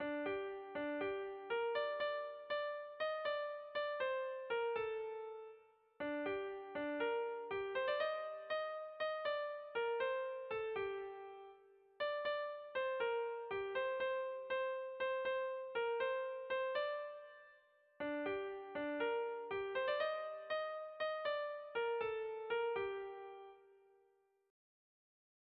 Erlijiozkoa
Zortziko txikia (hg) / Lau puntuko txikia (ip)
A-A2-B-A2